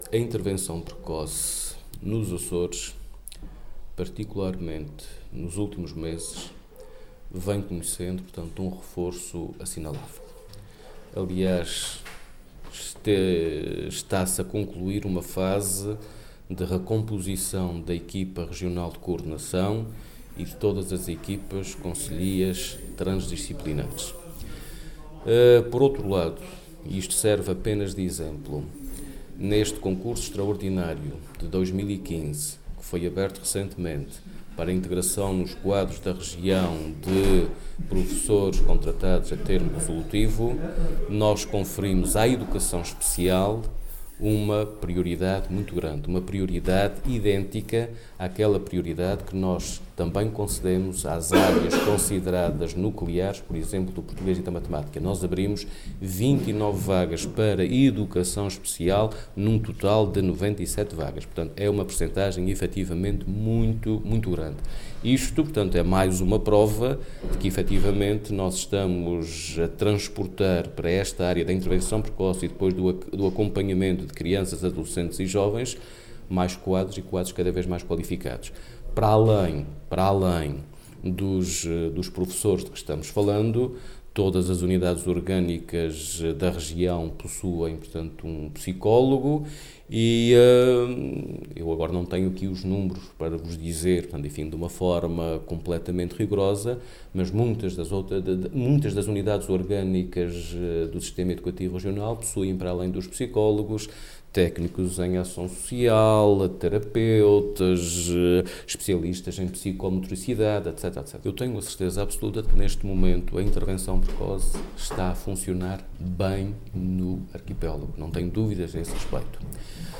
Avelino Meneses, que falava aos jornalistas no final de uma audição na Comissão de Assuntos Sociais da Assembleia Legislativa, referiu, a título de exemplo, o empenho do Governo na resolução deste problema ao dar uma “prioridade muito grande” aos profissionais de educação especial no recente concurso extraordinário de 2015 de integração nos quadros da Região de professores contratados a termo resolutivo.